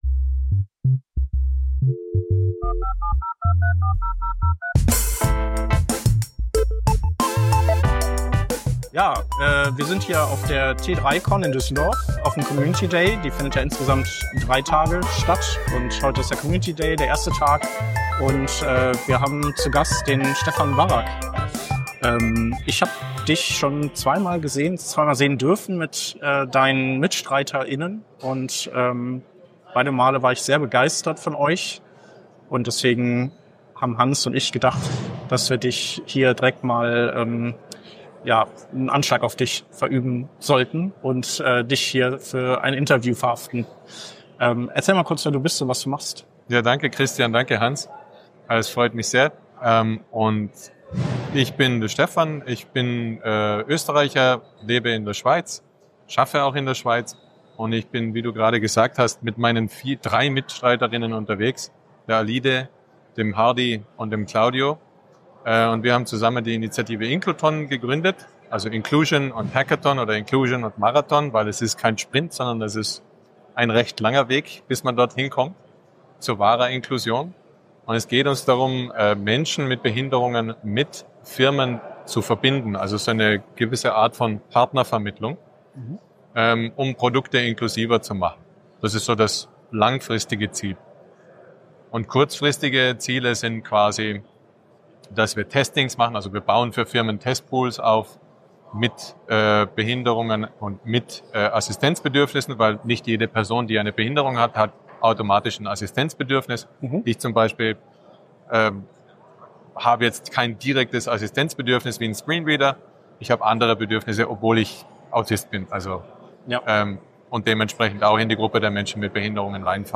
Working Draft ist ein wöchentlicher News-Podcast für Webdesigner und Webentwickler
In dieser Folge sprechen wir zu zweit über unsere Eindrücke rund um den Government Site Builder (GSB) – ausgelöst durch unseren Besuch auf der T3CON in Düsseldorf.
Herausgekommen ist stattdessen eine kurze, leicht rantige Bestandsaufnahme darüber, wie schwer es ist, überhaupt belastbare Informationen oder Gesprächspartner:innen zum GSB zu finden.